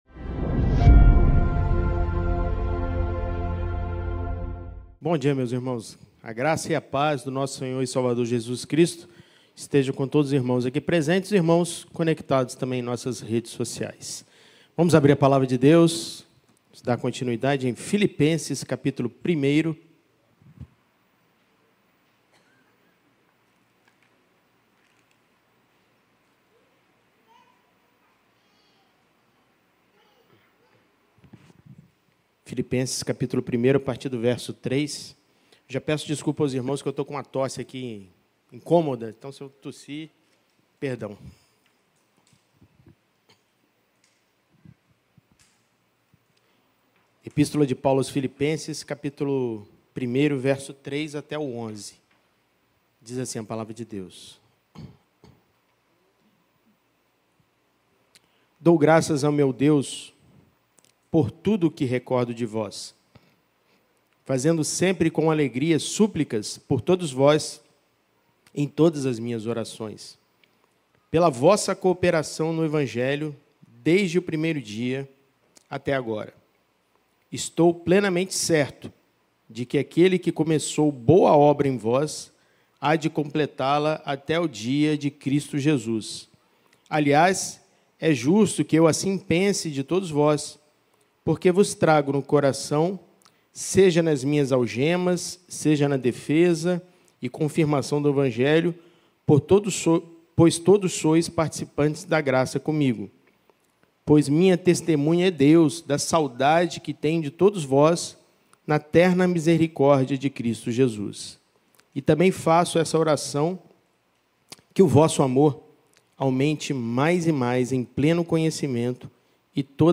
Escola Bíblica Dominical